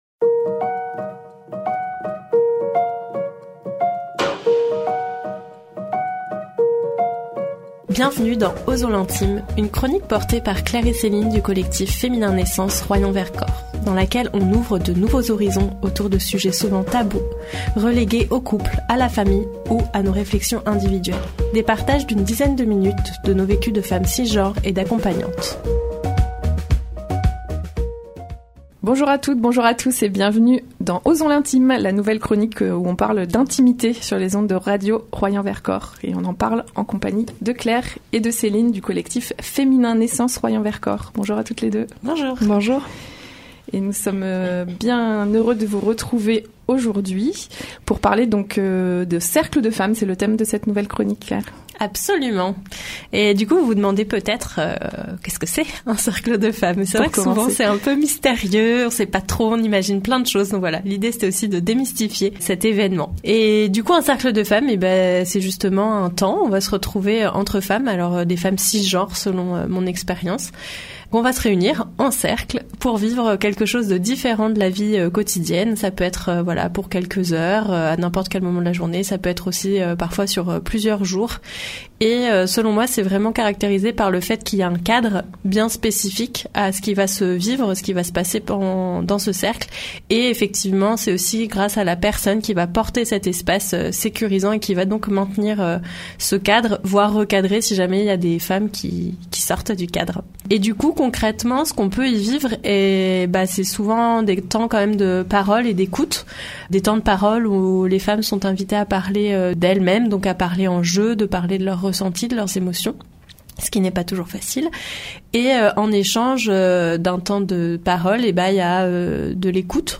Une toute nouvelle chronique à découvrir sur les ondes de Radio Royans Vercors : Osons l’intime !